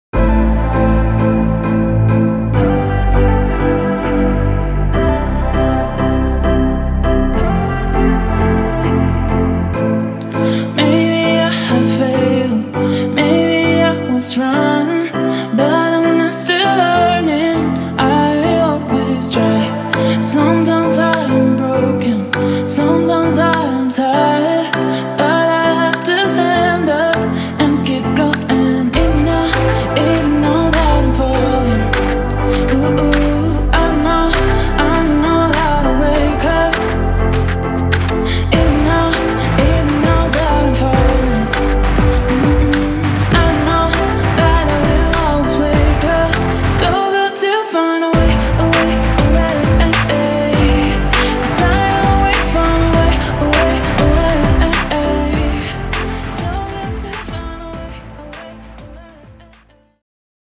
• 40 Guitar Loops Wet/Dry